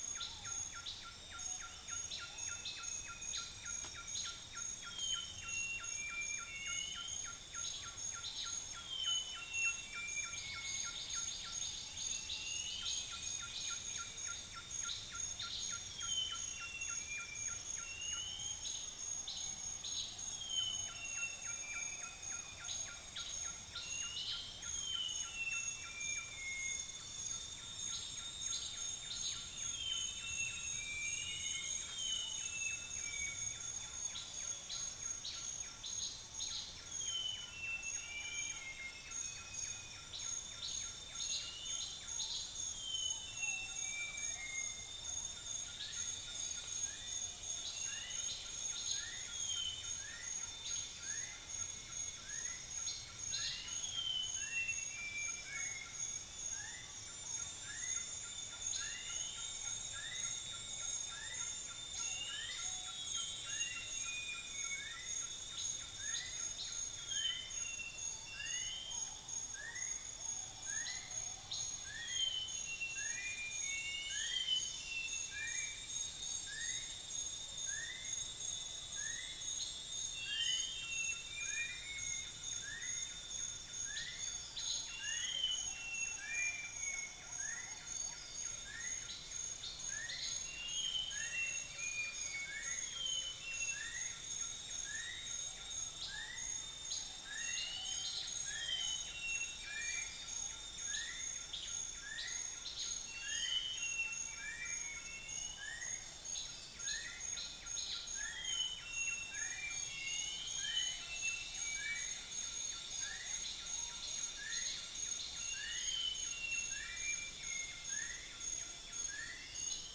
Upland plots dry season 2013
Actenoides concretus
Rhyticeros undulatus
Mixornis gularis
Malacopteron magnirostre
Trichixos pyrropygus